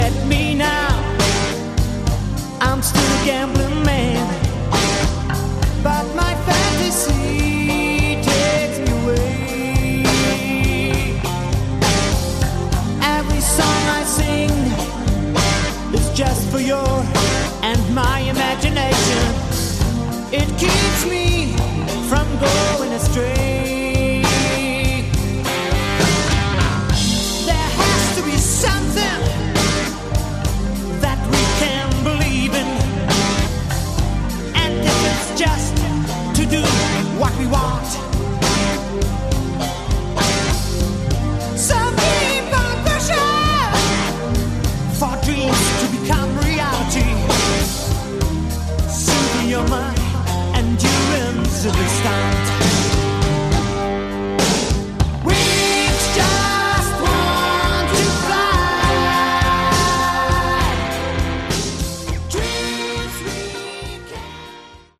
Category: AOR
vocals
guitars, keys, bass
drums